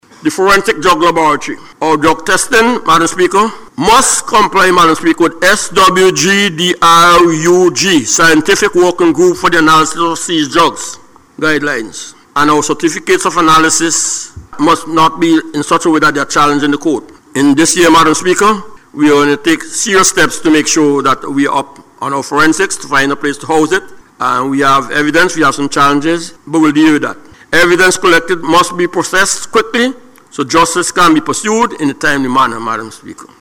Deputy Prime Minister and Minister of National Security, Major the Hon. St. Claire Leacock made this statement while contributing to the 2026 Budget Debate.